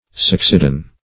Succedane \Suc"ce*dane\, n.